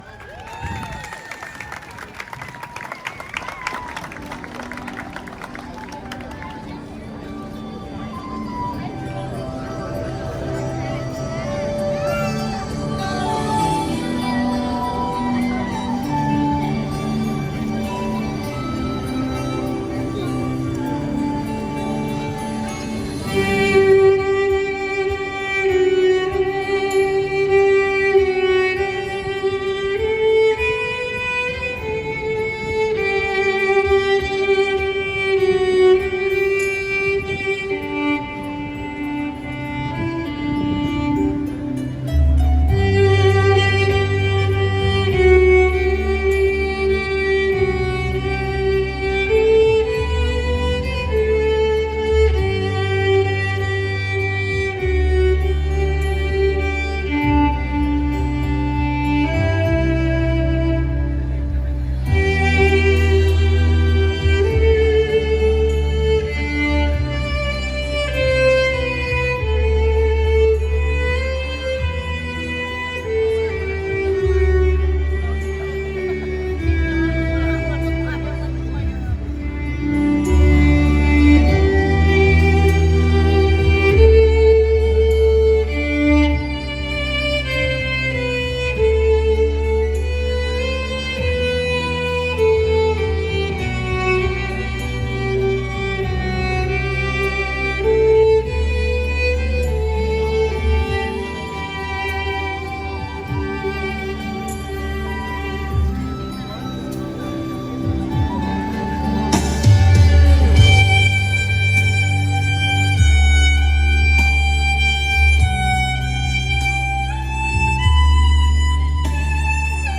לאו דוקא של שירים מוכרים מנגינות רגועות שנחמד לשמוע תו"כ לימוד וכד' תודה. רצ"ב נעימה מהסגנון שאני מחפשת